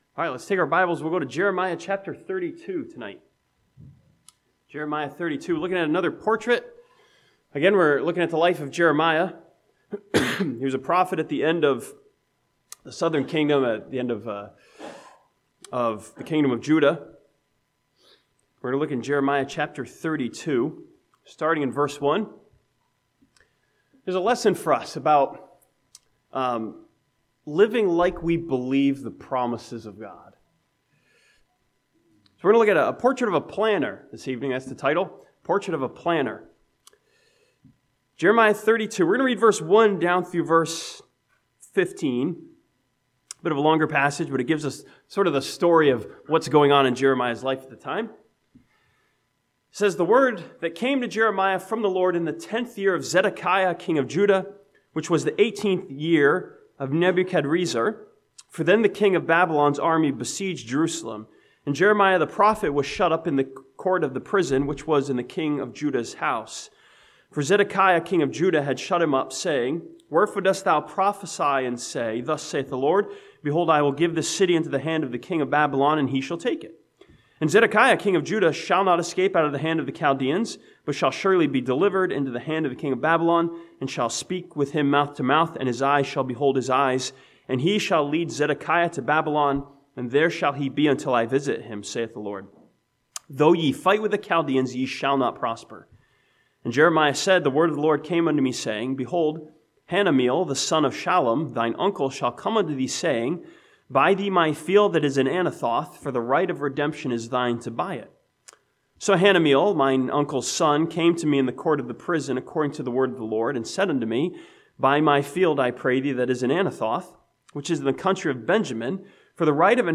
This sermon from Jeremiah chapter 32 looks at Jeremiah as he makes plans based on the promises of God.